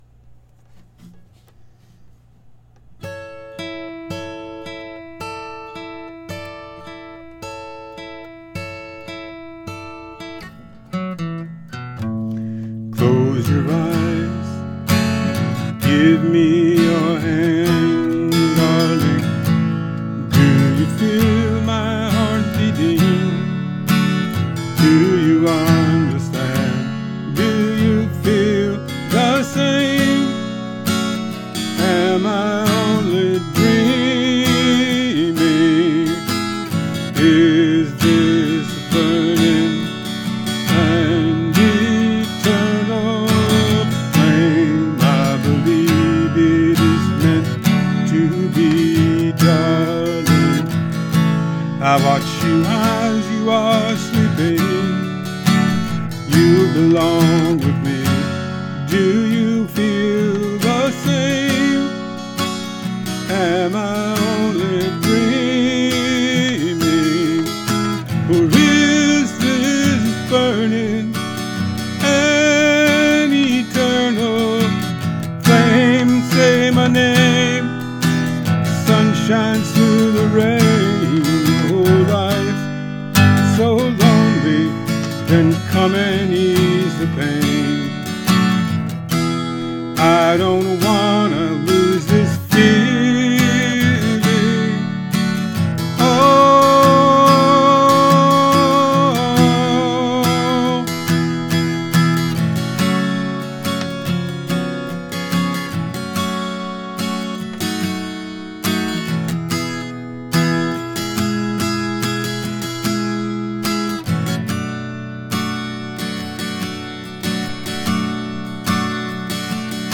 Here's my cover.